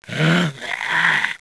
Index of /svencoop/sound/paranoia/zombie
zo_alert30.wav